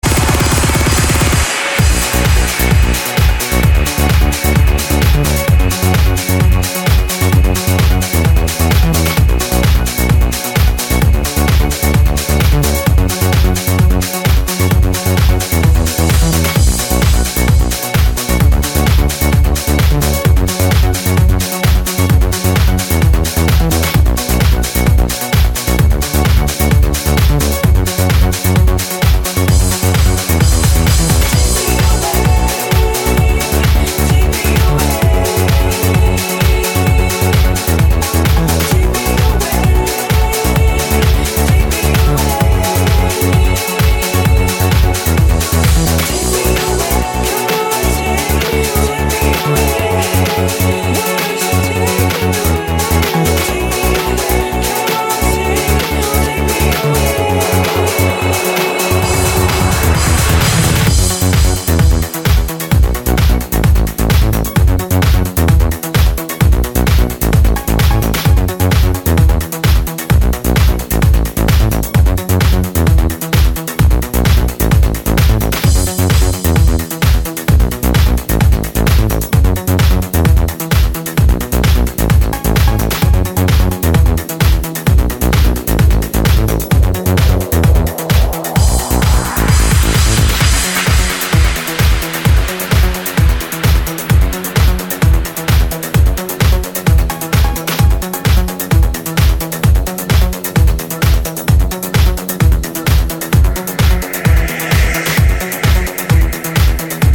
Друзья это (Electro house)